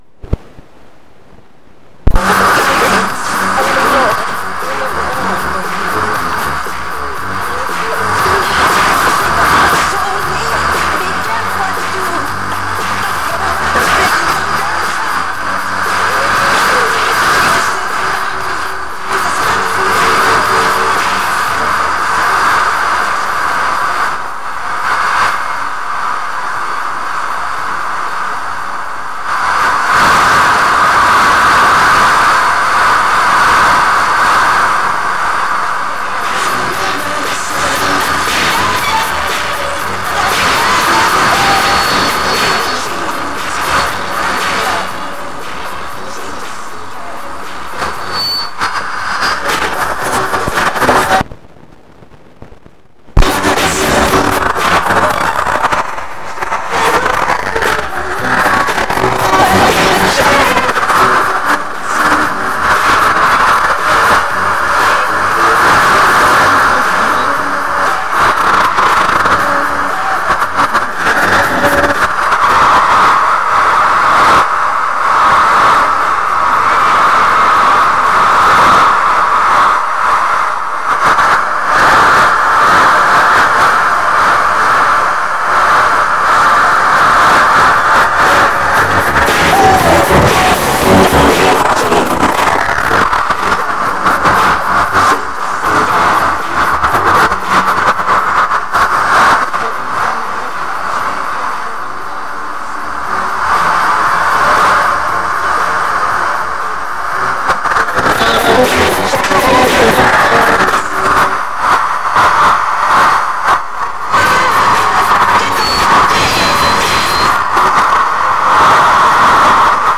This example below I projected pink noise through the ultrasound speaker into the room. My parabolic microphone “shows” the structure of the pink noise in room space.  You get a sense that as the pink noise bounces off the walls it fills the room completely.
With the parabolic microphone switched on you hear the details as in the example below.
In the example, the transmitter is moved towards and away from the ultrasound speaker and while away the interaction is less and hence the volume of the song is lower.
white noise ultrasound and transmitter playing billie jean
white-noise-ultrasound-and-transmitter-billie-jean.mp3